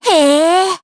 Cecilia-Vox_Happy4_jp.wav